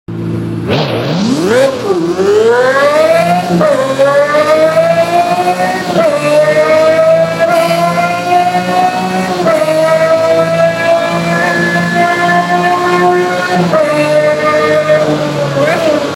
just Listen To This V12 Masterpiece Of an Engine..